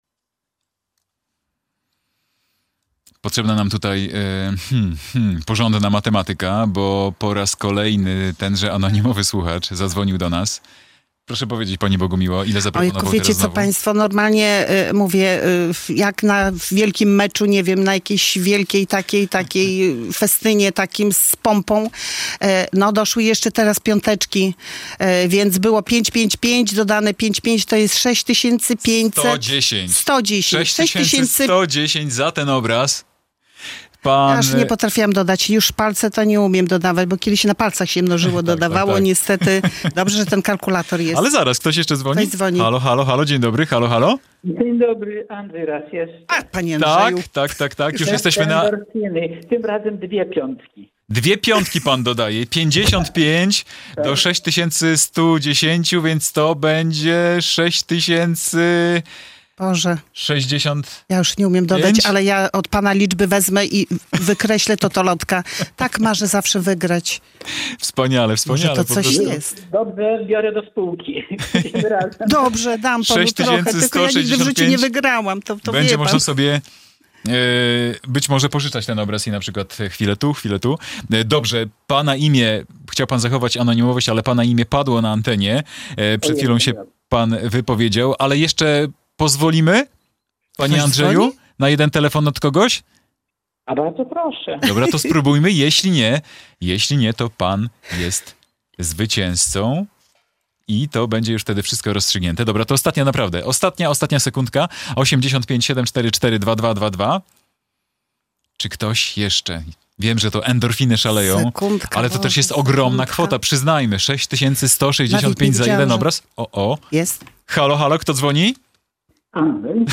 Finałowa licytacja trzeciego obrazu | Pobierz plik.